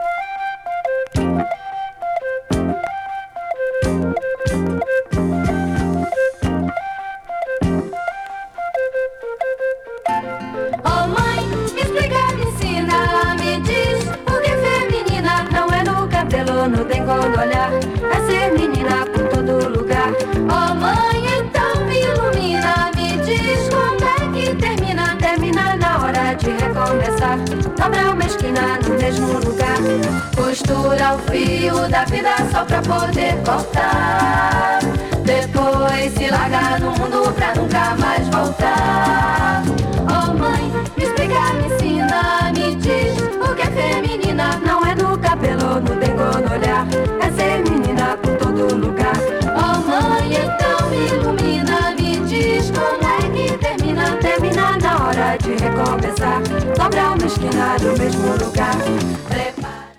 70s LATIN / BRASIL 詳細を表示する